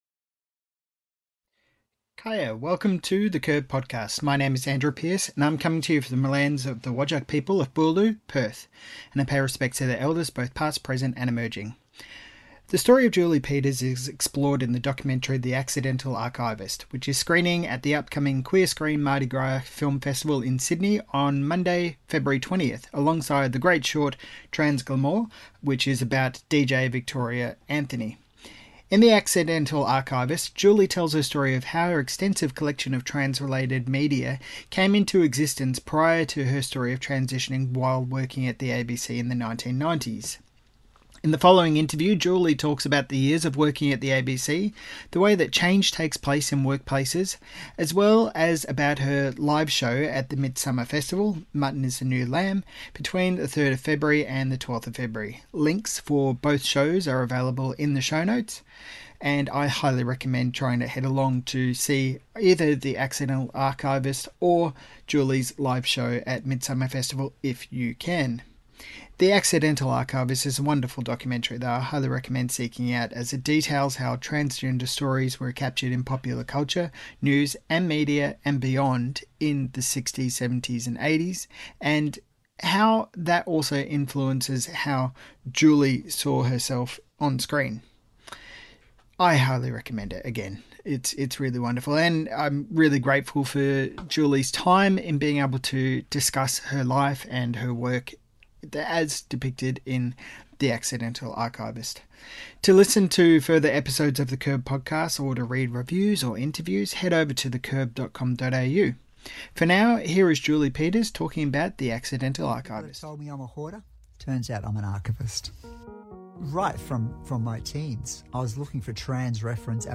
In the following interview